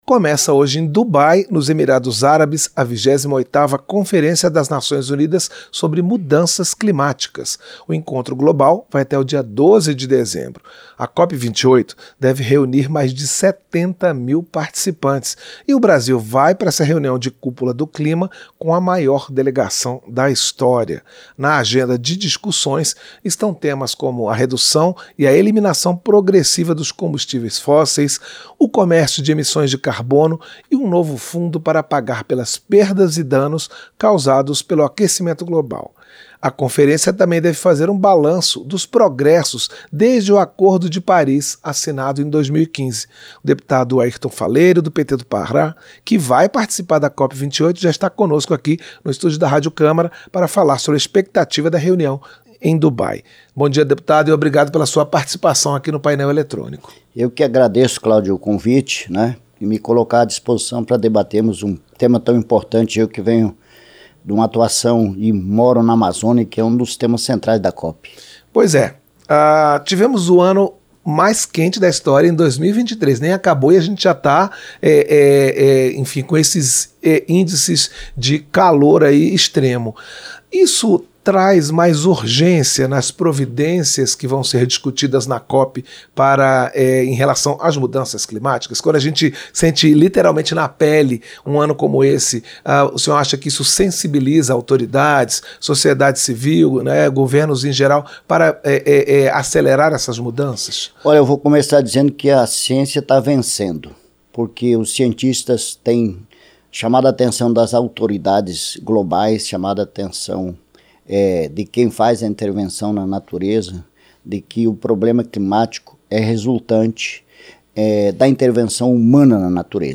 Entrevista - Dep. Airton Faleiro (PT-PA)